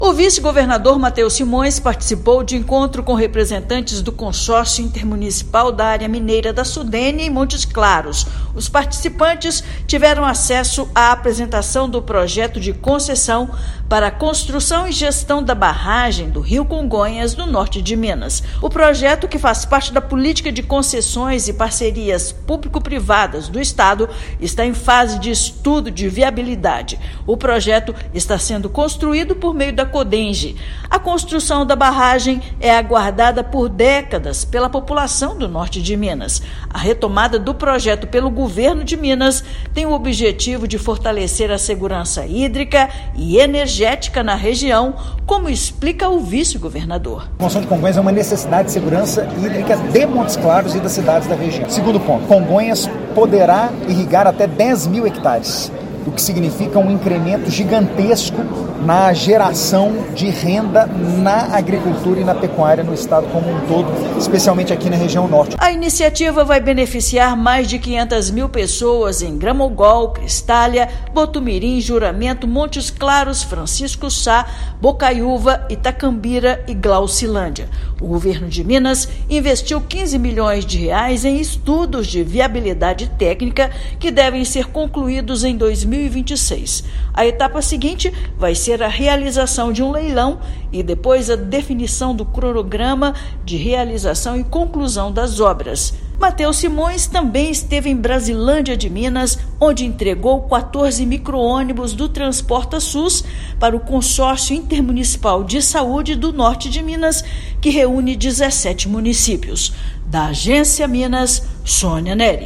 [RÁDIO] Em Montes Claros, vice-governador acompanha apresentação do projeto Barragem de Congonhas
Com estudos de viabilidade em andamento, iniciativa tem como objetivo melhorar fornecimento de energia, irrigação e infraestrutura hídrica no Norte de Minas. Ouça matéria de rádio.